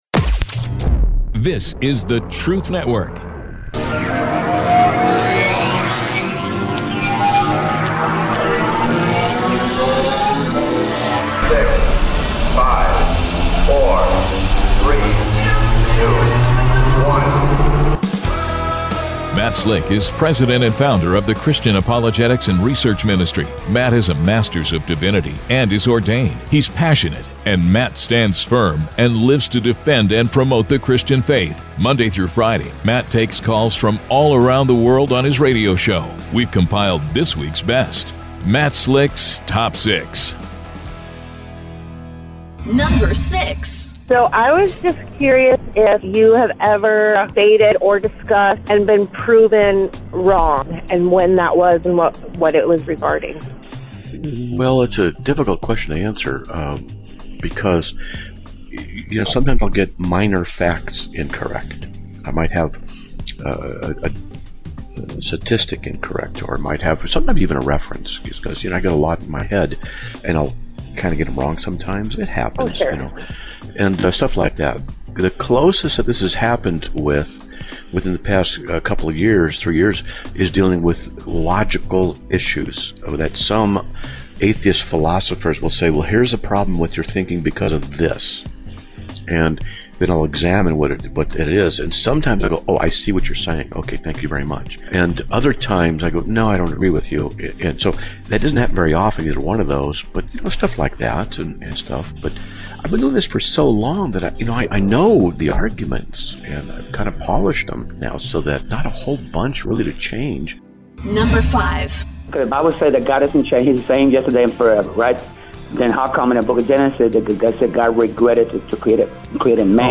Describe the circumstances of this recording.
The sound bites are from Roy Rogers